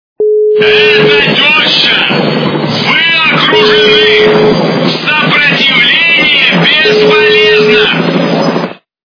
» Звуки » Смешные » Голос из вертолета - Это тёща, вы окружены. Сопротивление бесполезно!
При прослушивании Голос из вертолета - Это тёща, вы окружены. Сопротивление бесполезно! качество понижено и присутствуют гудки.